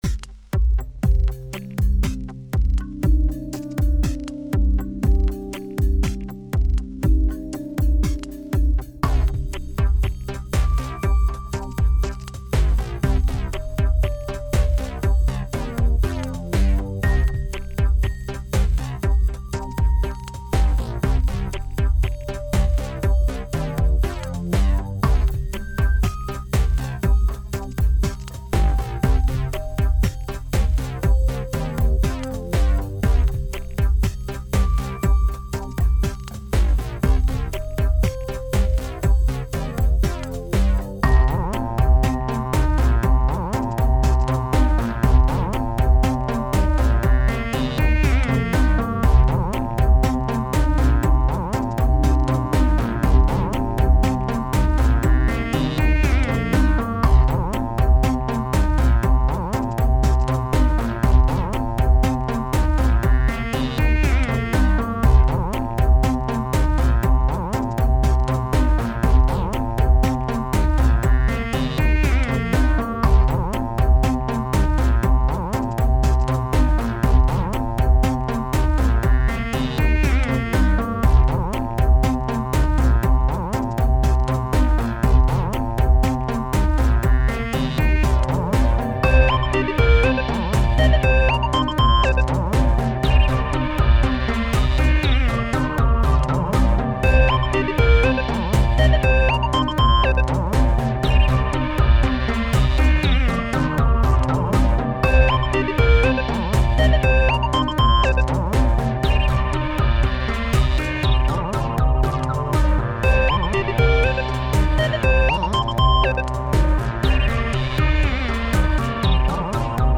vocal, keyboards